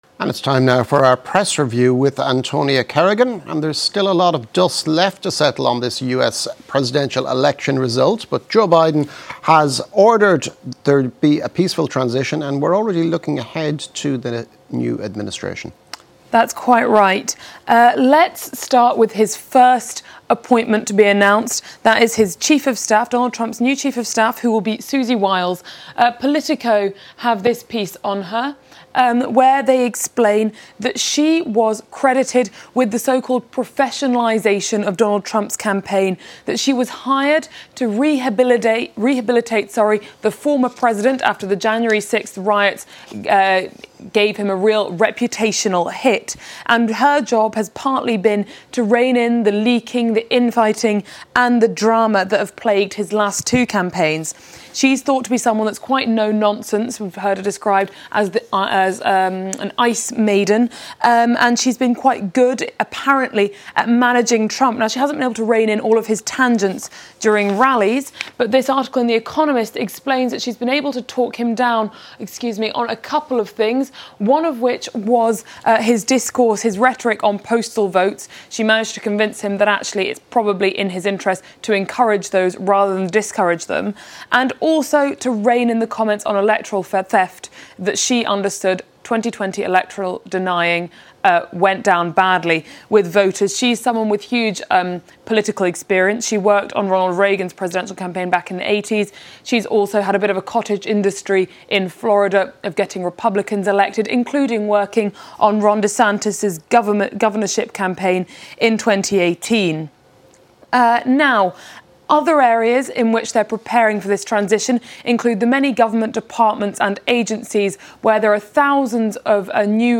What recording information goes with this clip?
Sunday Worship